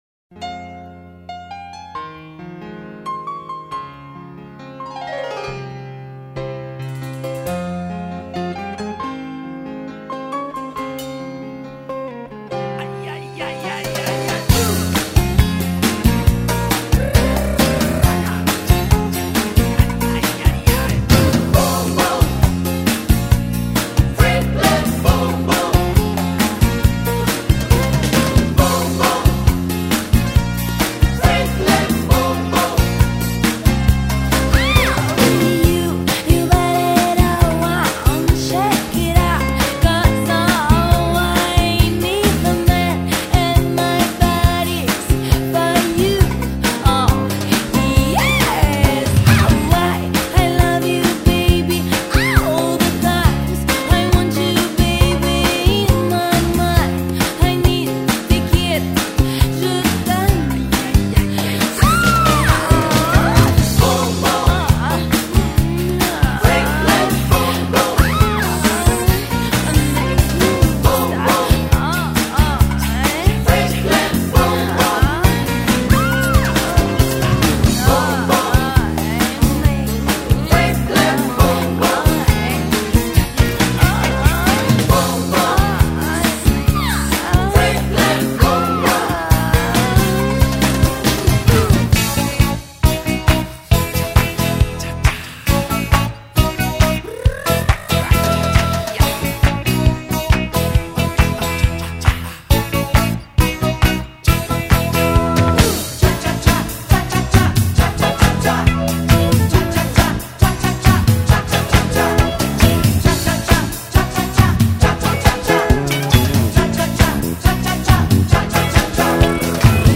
Tags: Discoteca